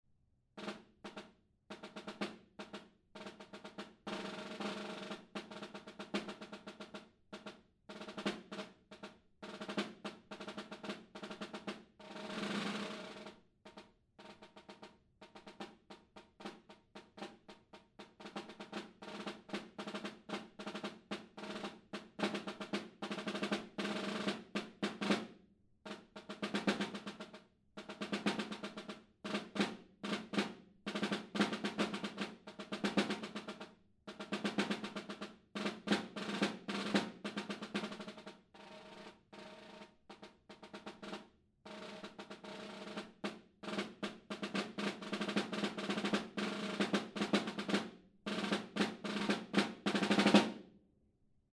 Encore 2026 Snare Drum Solos
Demo